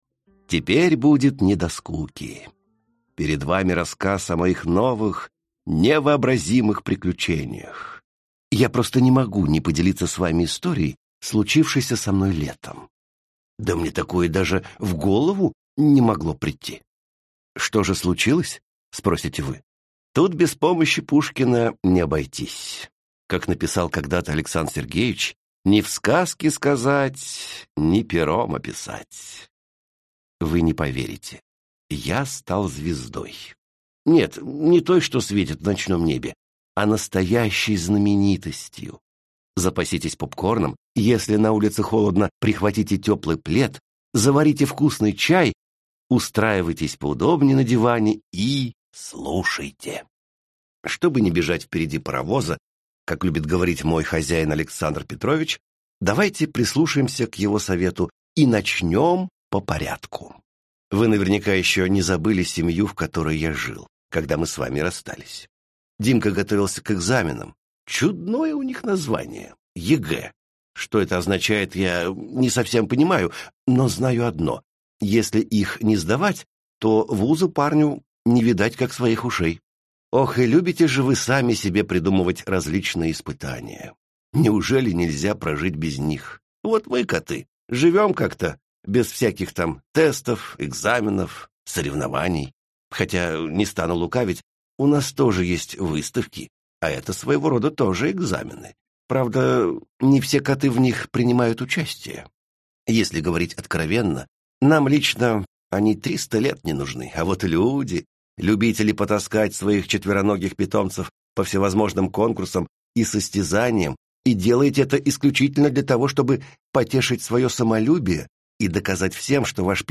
Аудиокнига Подлинный Сократ | Библиотека аудиокниг
Прослушать и бесплатно скачать фрагмент аудиокниги